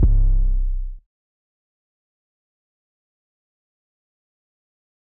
808 Rich .wav